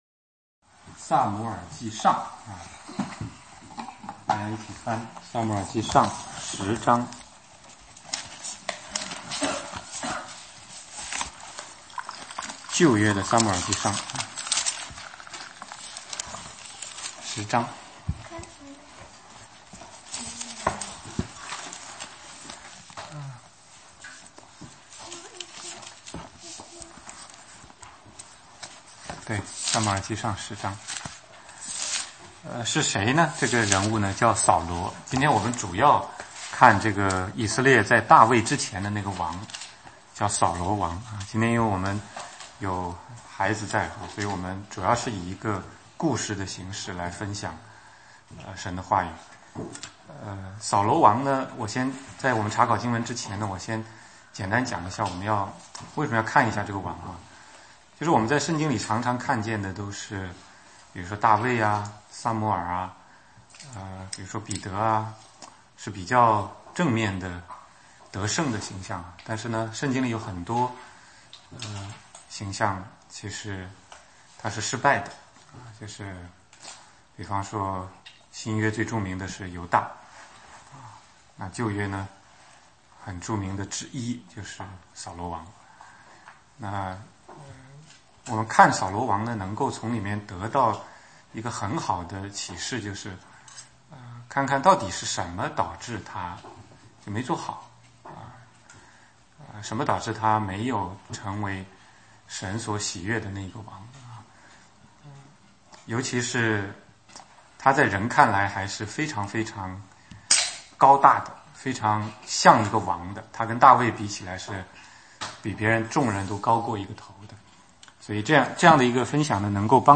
16街讲道录音 - 撒母耳记上13-15,扫罗的悲剧